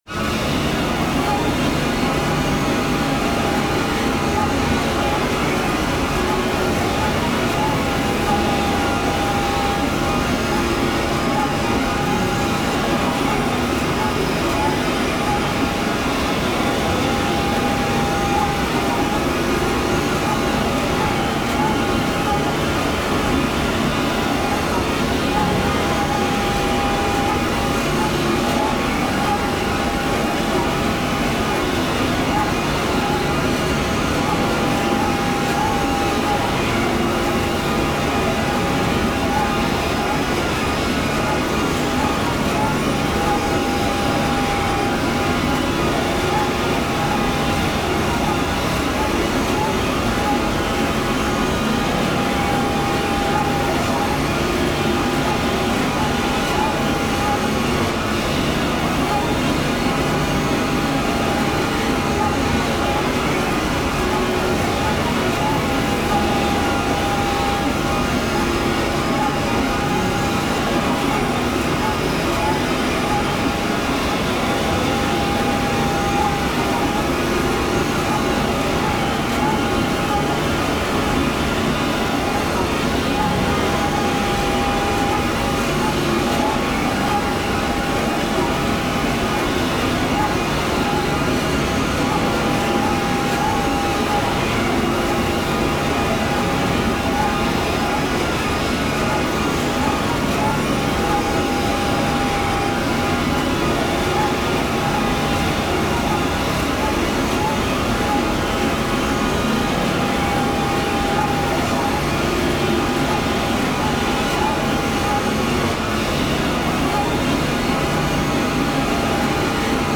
5段階のホラーテクスチャ音源の第五段階。 ついに異形と接触し、意識が途切れる直前に恐怖心を具現化したような音が聞こえる。
タグ: ホラー/怖い 変わり種 コメント: 5段階のホラーテクスチャ音源の第五段階。